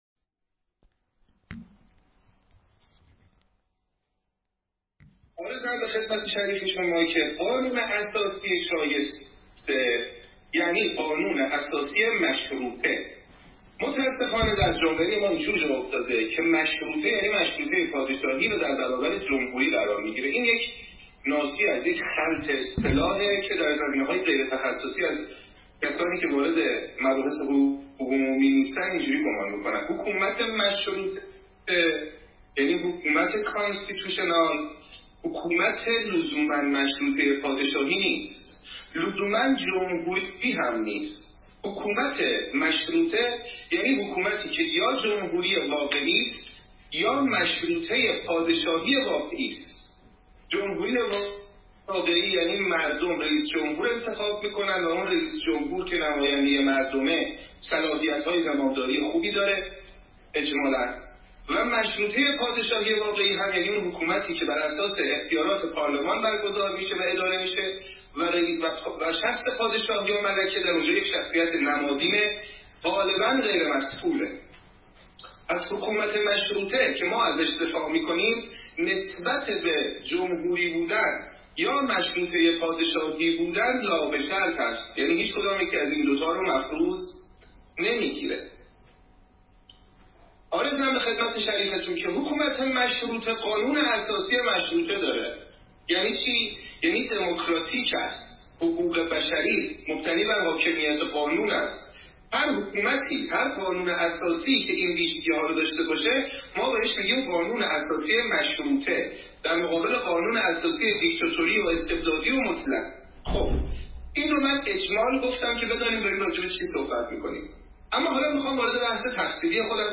سخنرانی
در روز قانون اساسی برای دانشجویان دانشگاه یزد ایراد شده است.